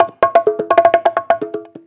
Conga Loop 128 BPM (4).wav